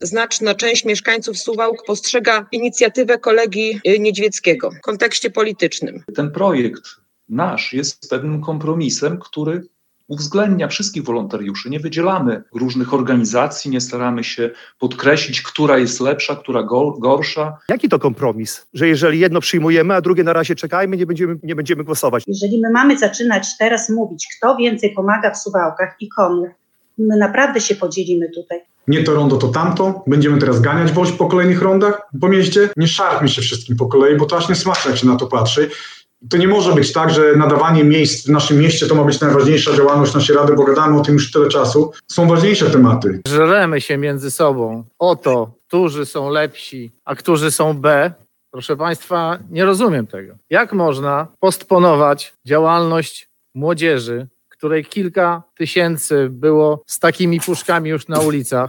Tak długiej debaty, jak ta w sprawie nadania nazwy Wielkiej Orkiestry Świątecznej Pomocy rondu w Suwałkach dawno na sesji Rady Miejskiej nie było.